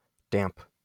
enPR: dămp, IPA/dæmp/, SAMPA/d{mp/
wymowa amerykańska?/i